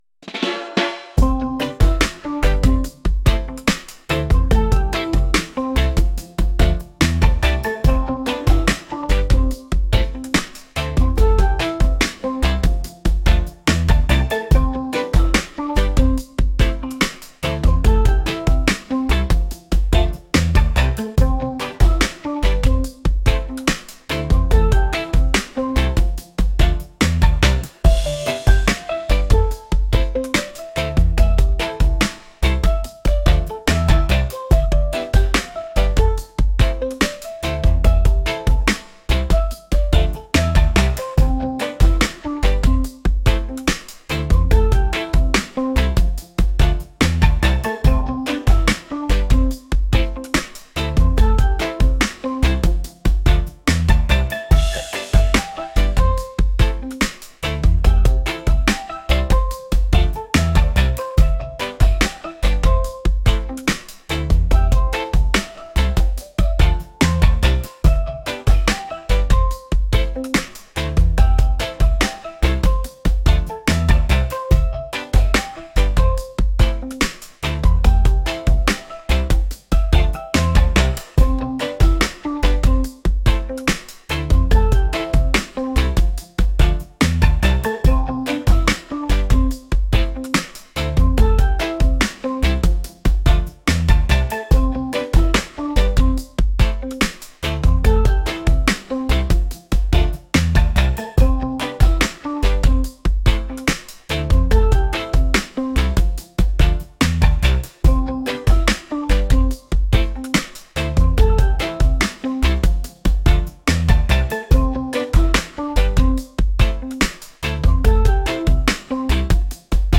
laid-back | reggae